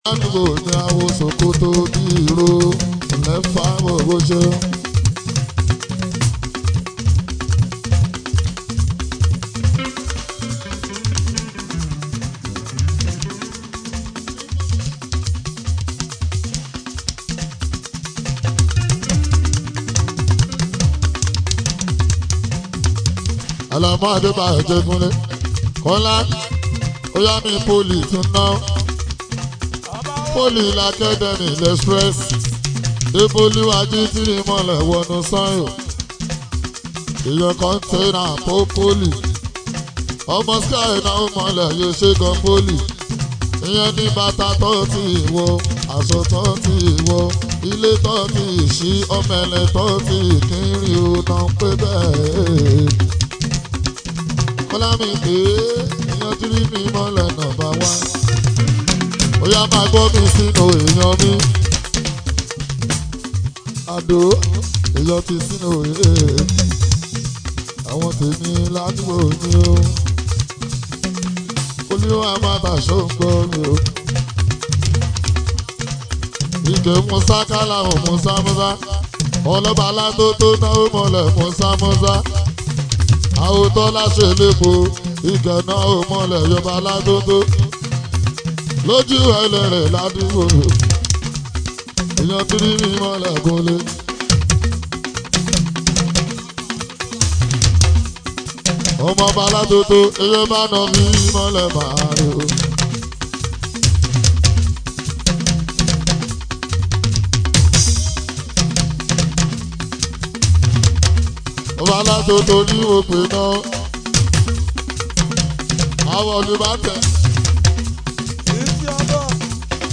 Yoruba Fuji song
Fuji song